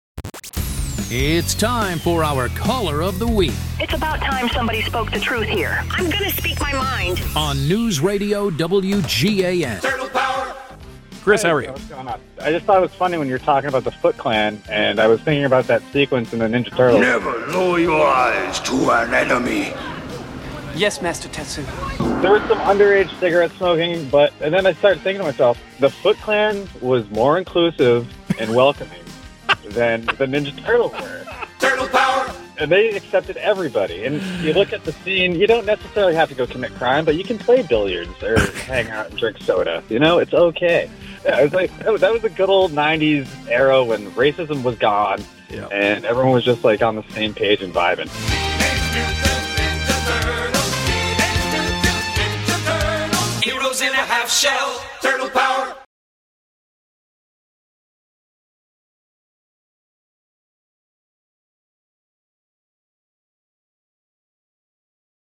Caller Of The Week 2/27/26